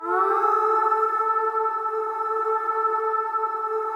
WAIL RIP 6.wav